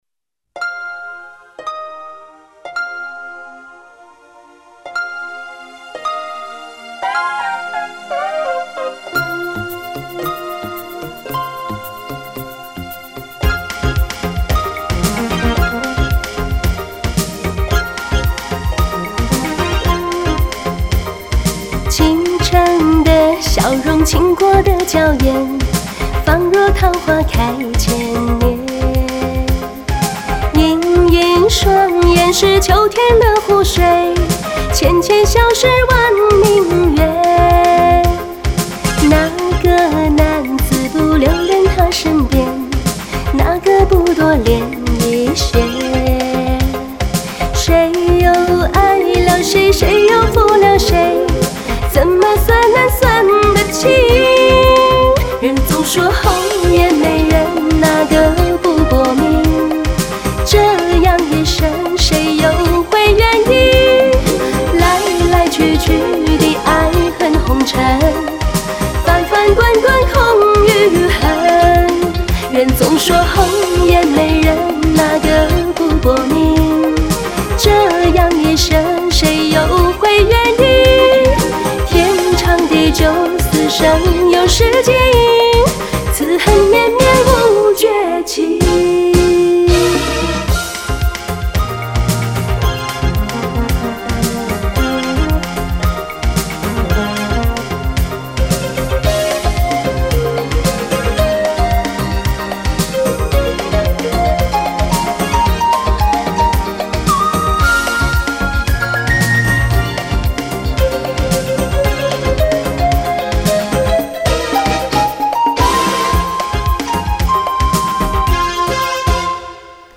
• 音色音调舒服。
这歌唱的温柔甜美，倾国倾城，当原唱听了~~^_^